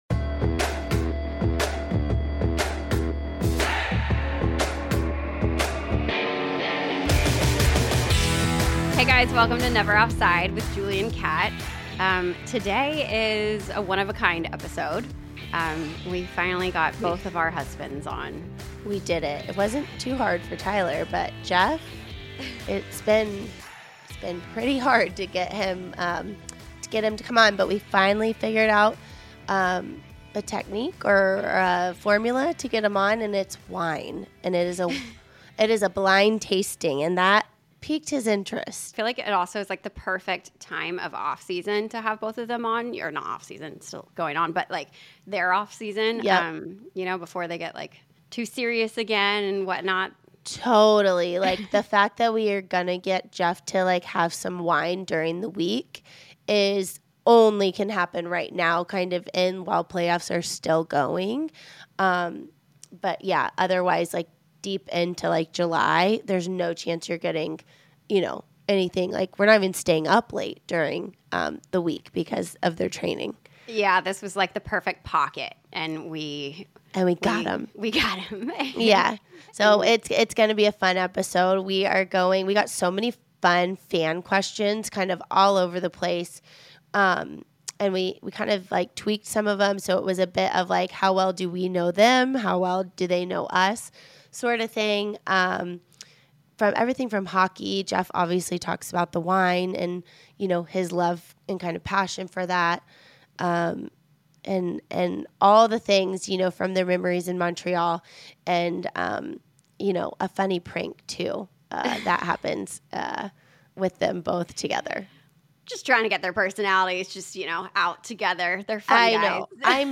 The foursome uncorked some wine and catch up, sharing memories from their years in the NHL, with a few fantastic stories sprinkled in.